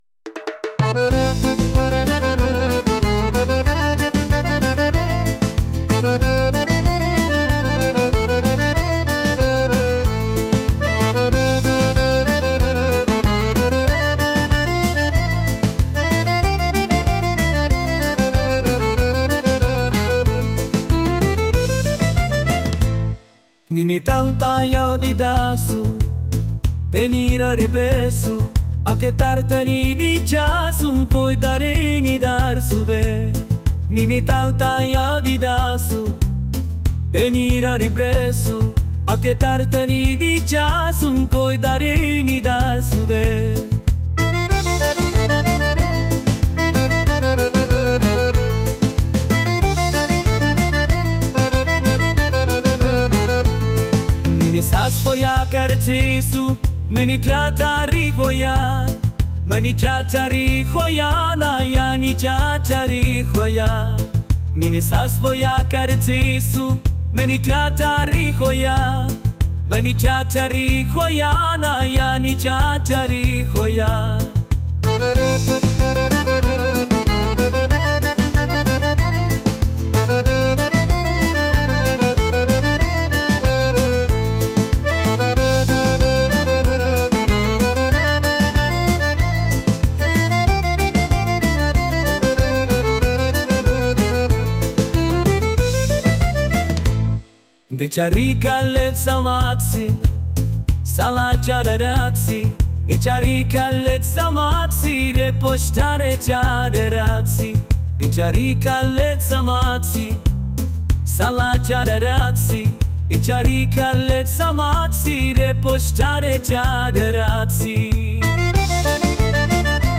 traditional | soulful